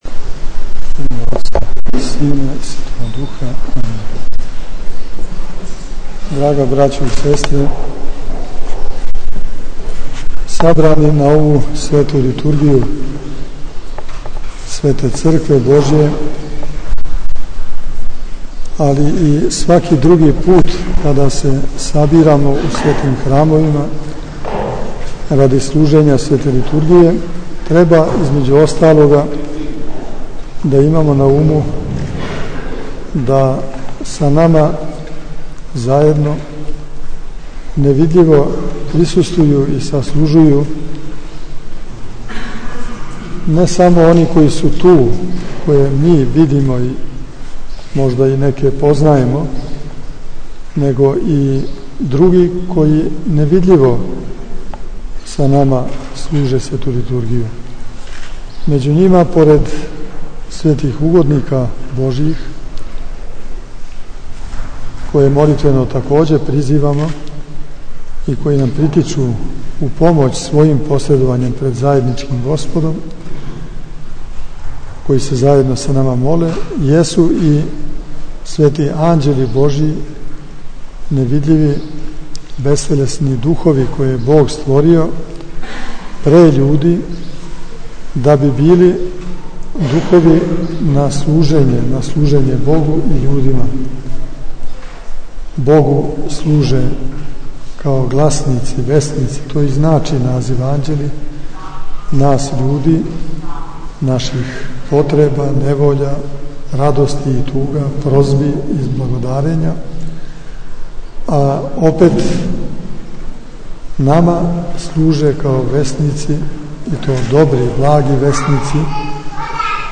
Обитељ Светоархангелског манастира у Ковиљу прославила је у понедељак, 26. јула текуће године престолни празник свога храма, Сабор Светог Архистратига Гаврила.
• Беседа Епископа Иринеја: